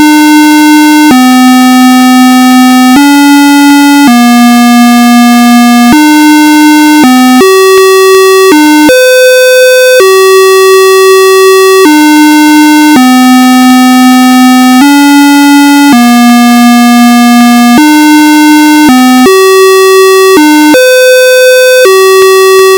An imagined early draft of something like Beethoven’s 5th symphony.
Channels: 1 (mono)